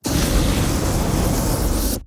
Free Fantasy SFX Pack
Firespray 2.ogg